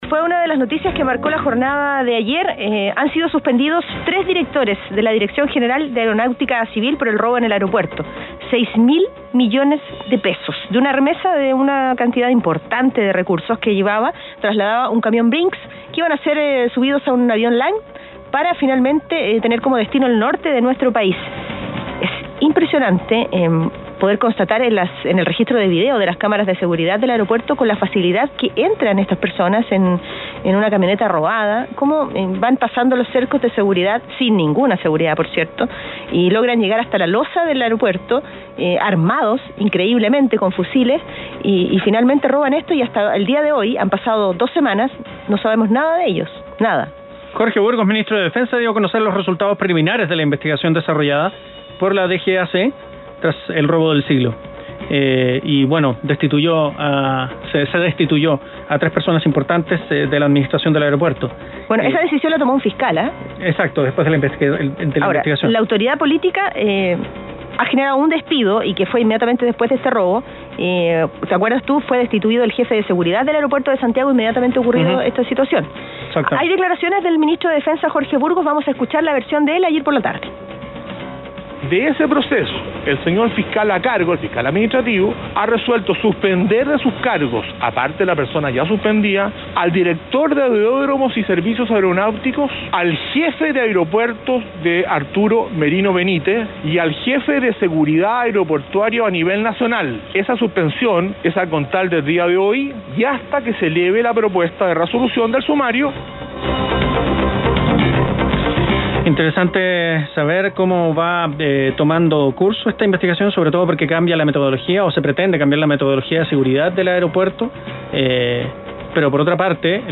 Entrevista a experto en seguridad, David Rozowski en Radio Bío Bío